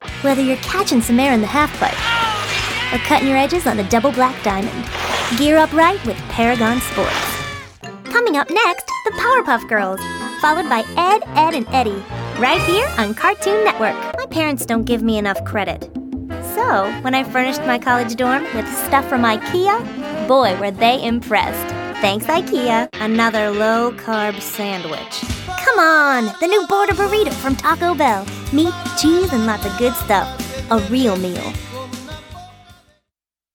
Female Voice Over, Dan Wachs Talent Agency.
Young, Vibrant, Effervescent
Commercial